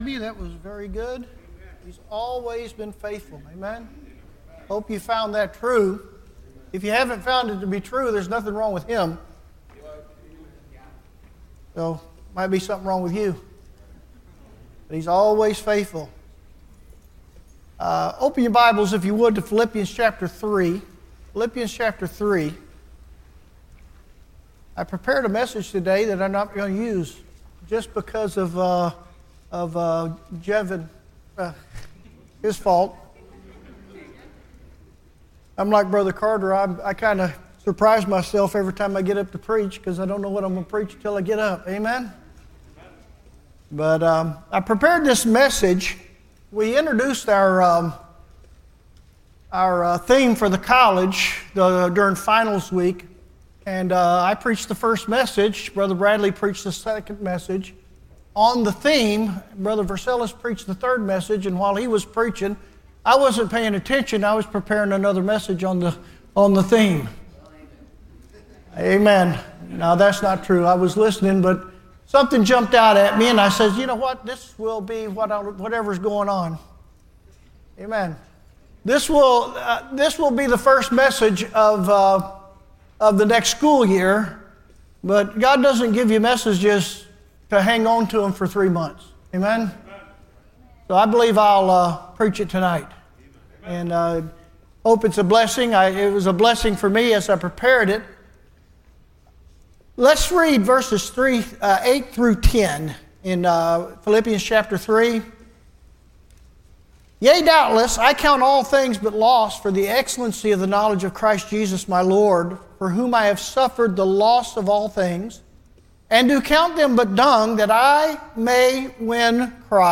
Service Type: Wednesday